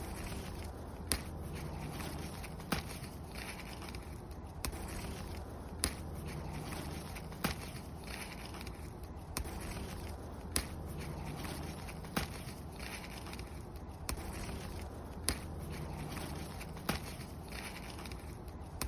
Tiếng Ngắt rau, Hái rau củ quả…
Tiếng dùng kéo, móng tay Cắt rau, Ngắt hái rau củ quả cật cật…
Thể loại: Tiếng động
Với âm thanh phụ đặc trưng như tiếng bẻ rau, hái trái, nhặt rau, tiếng động tự nhiên này còn được xem như một sound effect lý tưởng cho các video ASMR, vlog làm vườn, hoặc phim tài liệu.
tieng-ngat-rau-hai-rau-cu-qua-www_tiengdong_com.mp3